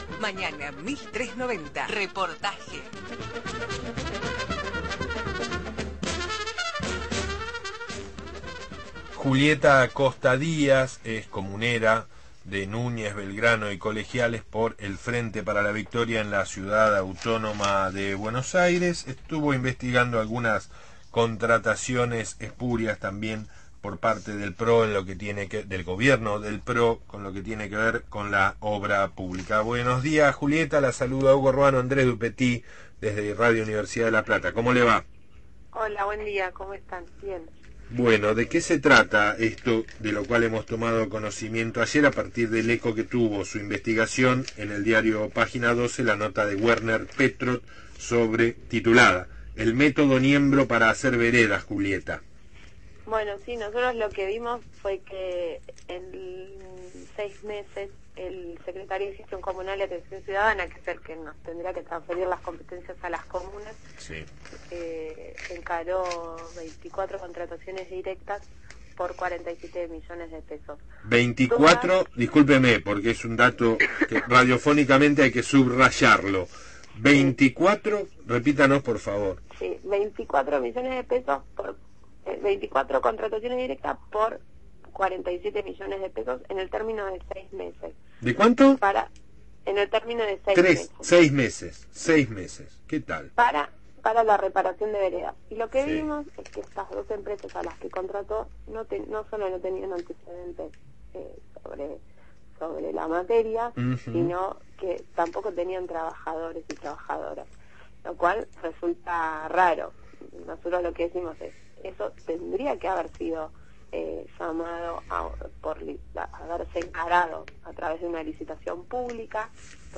Julieta Costa Díaz, comunera porteña (por Nuñez, Colegiales y Belgrano) del Frente para la Victoria, dialogó